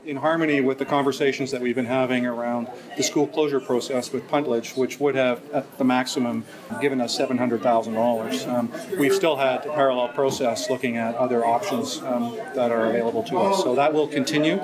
Board Chair Tom Weber says the district is still looking for ways to address its deficit.